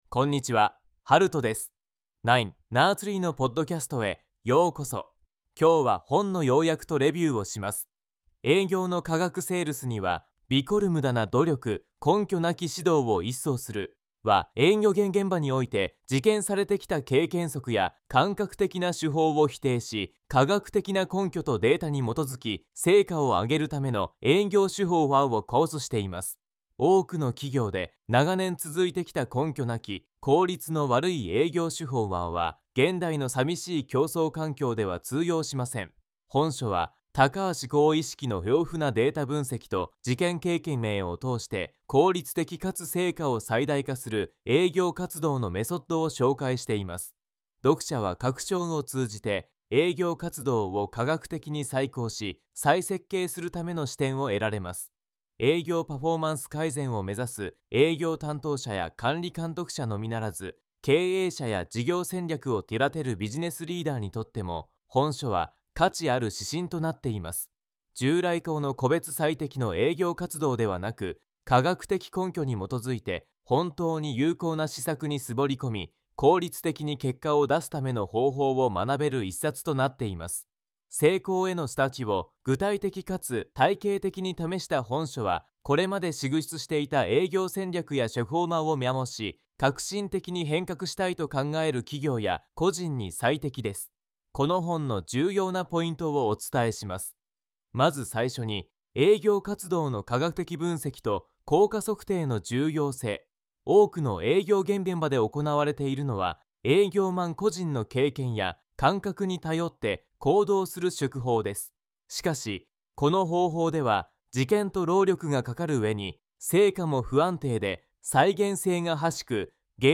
[レビュー] 営業の科学 セールスにはびこるムダな努力 (高橋浩一) 要約した.